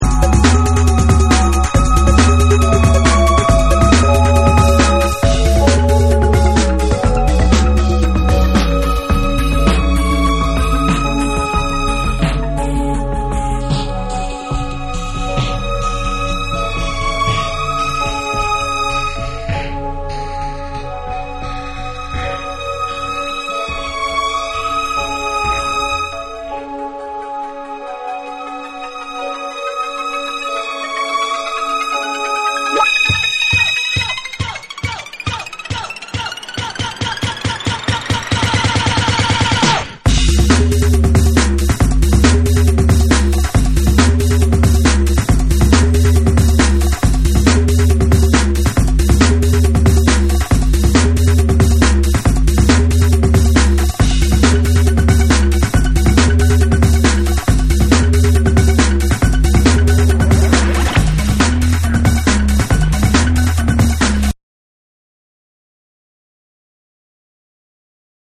BREAKBEATS / TECHNO & HOUSE